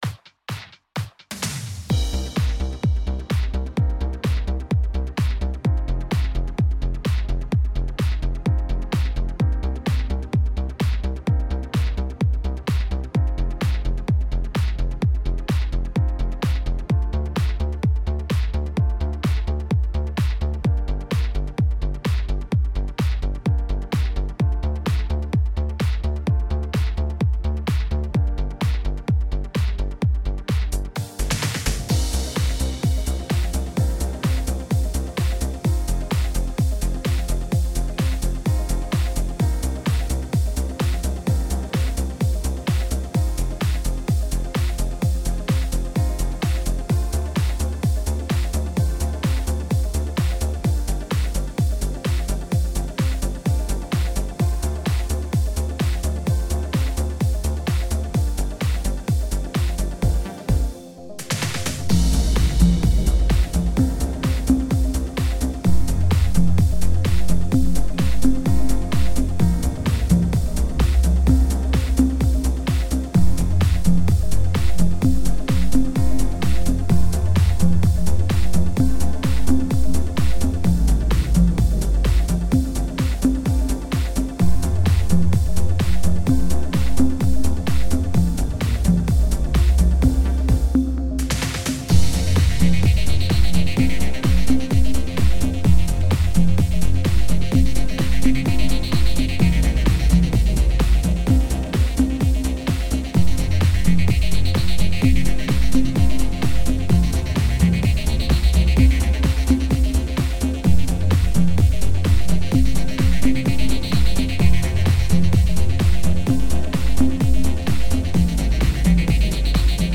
Experimental megamix